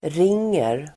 Uttal: [r'ing:er]
ringer.mp3